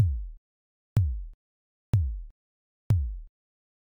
kick.ogg